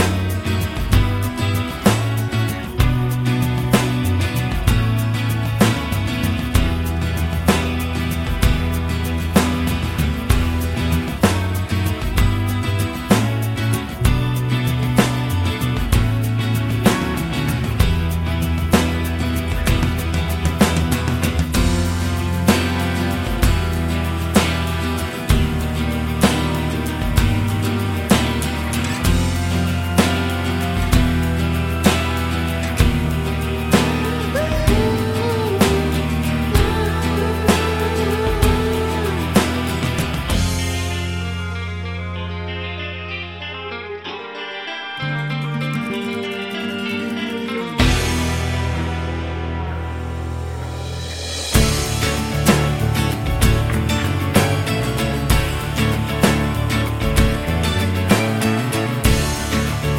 no Backing Vocals Indie / Alternative 5:22 Buy £1.50